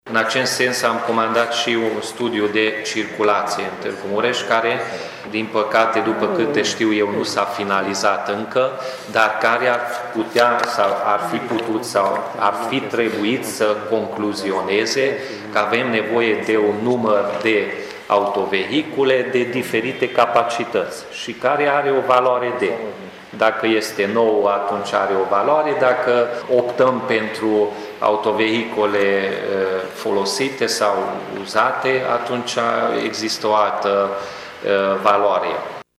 Viceprimarul Peti Andras a spus că ar trebui făcut un studiu pentru a se vedea nevoile reale din acest domeniu: